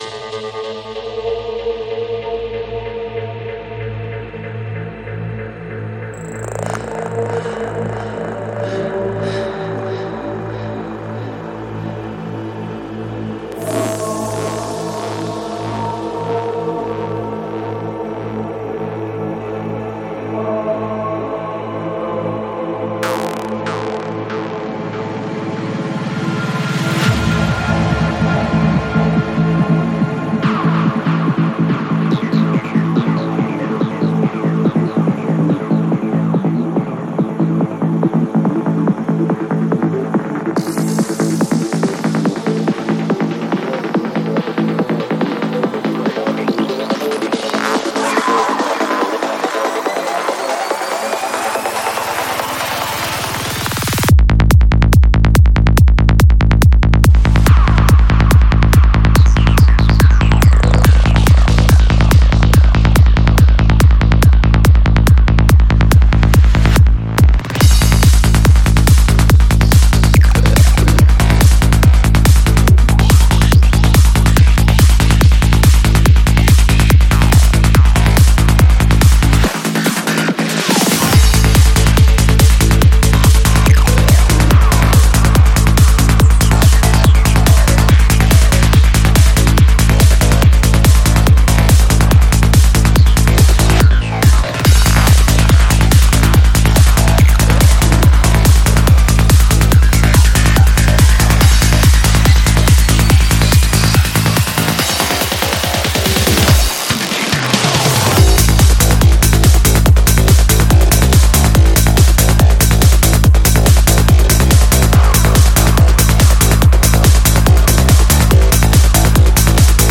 Жанр: Транс
Psy-Trance